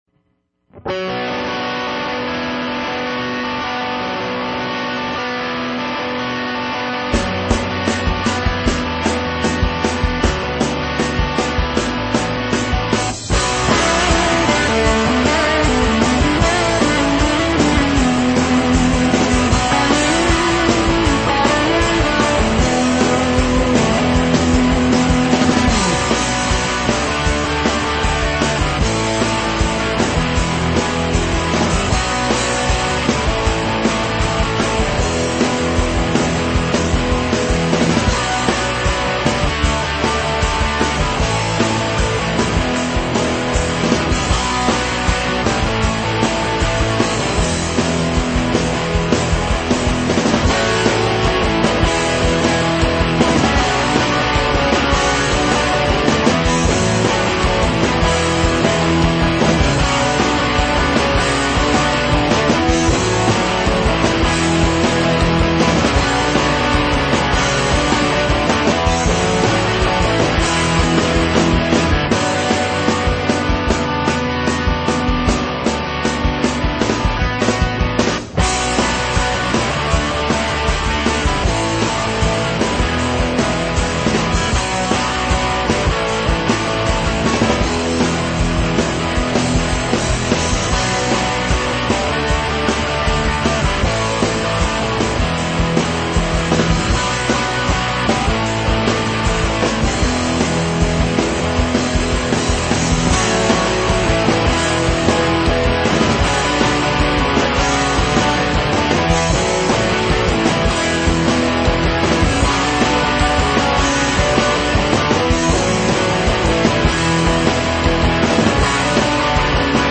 punk
metal
hard rock
rock
high energy rock and roll